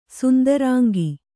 ♪ sundarāngi